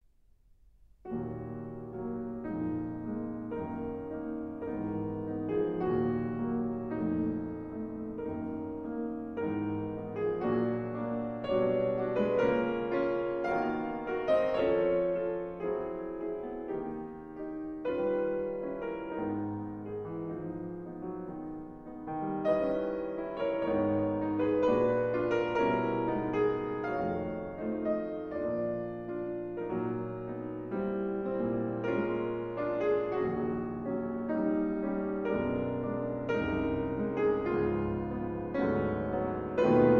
in E flat major: Andantino amabile